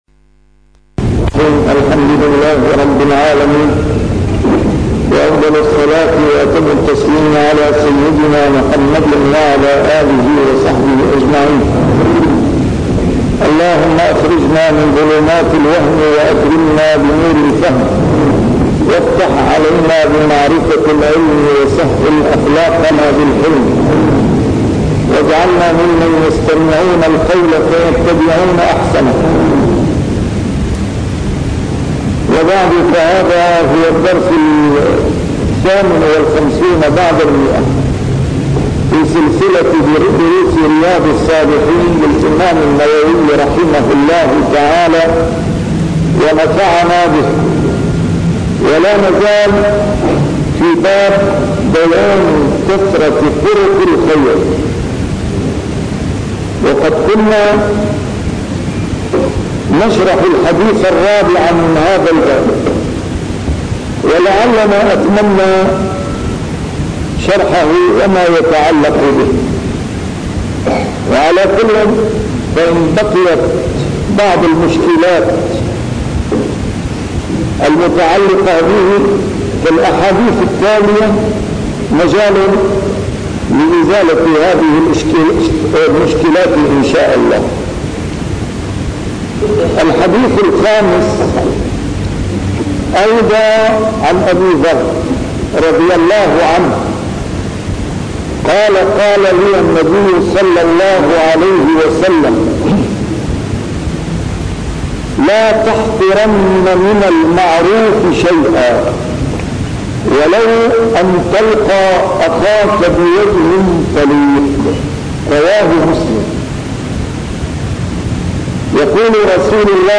A MARTYR SCHOLAR: IMAM MUHAMMAD SAEED RAMADAN AL-BOUTI - الدروس العلمية - شرح كتاب رياض الصالحين - 158- شرح رياض الصالحين: كثرة طرق الخير